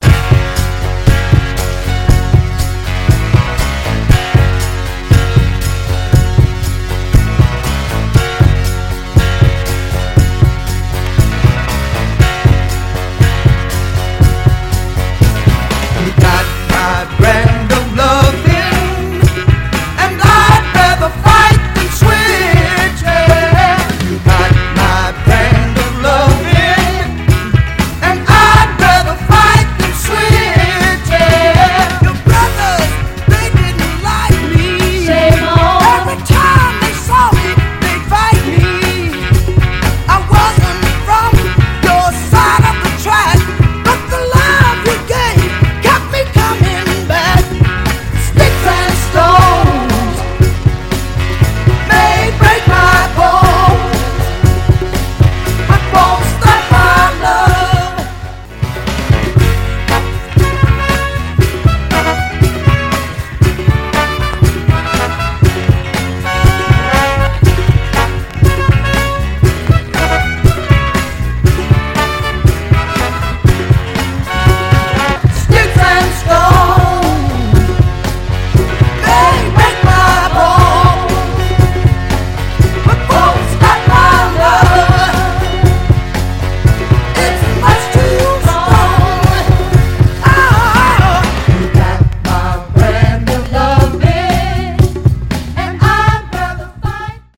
後半のホーン隊も勇ましいパーカッシヴ・ソウルです！
B面は後半に小キズあり、数回プチっと鳴りますがそれ以外はプレイ良好。
※試聴音源は実際にお送りする商品から録音したものです※